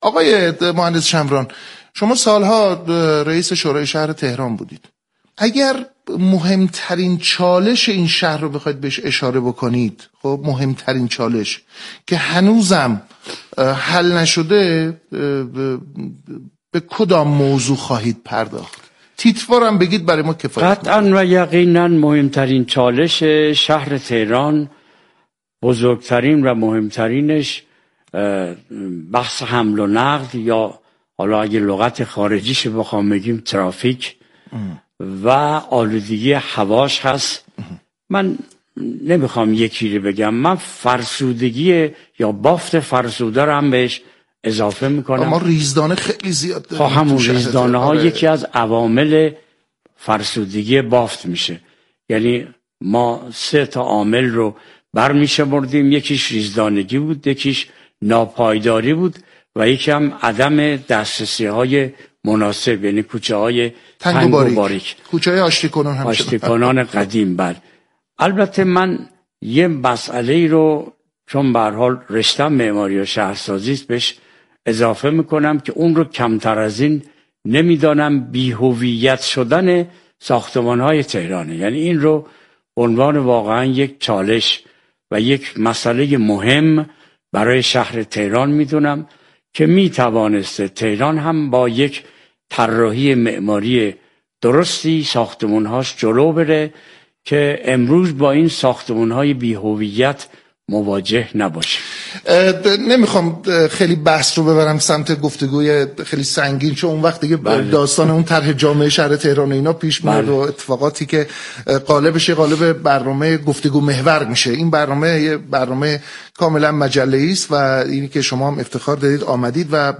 حمل و نقل و آلودگی هوا مهمترین چالش شهر تهران است به گزارش پایگاه اطلاع رسانی رادیو تهران، مهدی چمران رئیس شورای اسلامی شهر تهران در گفت و گو با «اینجا تهران است» اظهار داشت: فرسودگی بافت شهری، حمل و نقل و آلودگی هوا مهمترین چالش شهر تهران است.